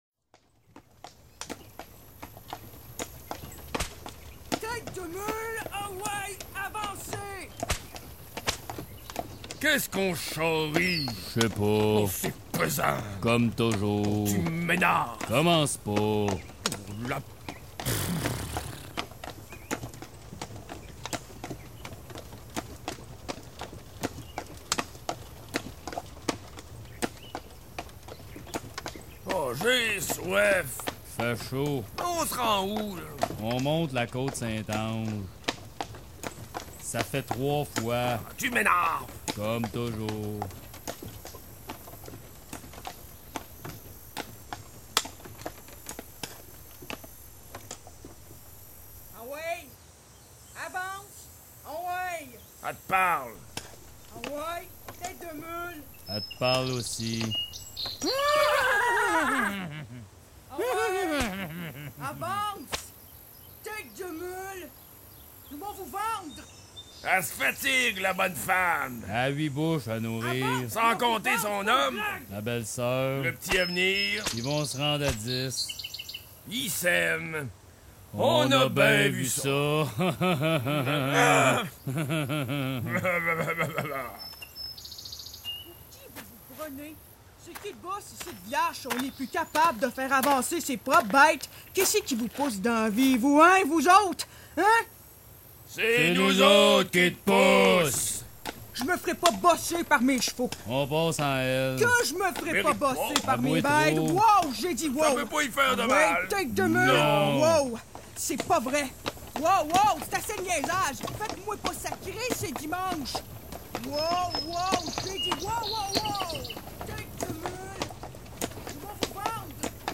Parcours audiothéâtraux
Ce dernier vous propose une série de capsules relatant, de façon parfois inattendue, la petite histoire du lieu ou du site devant lequel vous vous retrouvez. Faites un retour dans le temps en compagnie de personnages pour le moins... très colorés! Une réalisation du Théâtre 100 Masques.